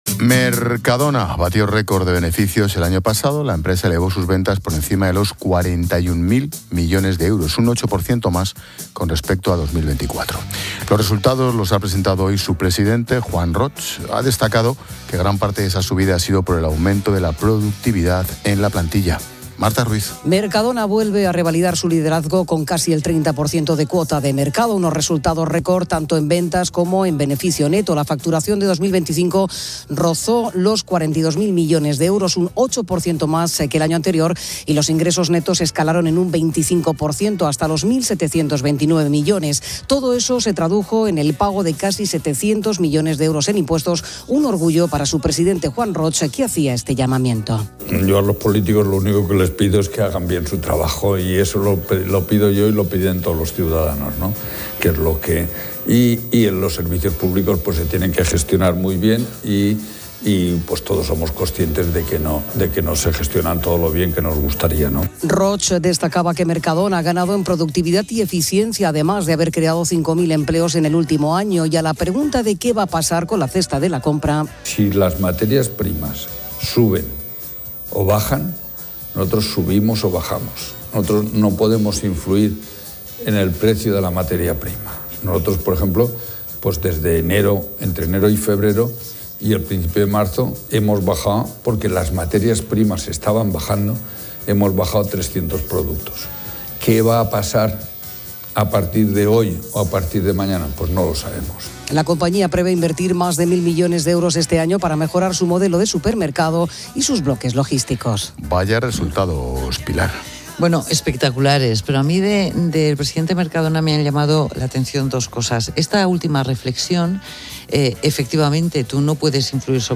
Expósito aprende en Clases de Economía de La Linterna con la experta económica y directora de Mediodía COPE, Pilar García de la Granja, sobre los resultados récord de Mercadona y la reflexión de Juan Roig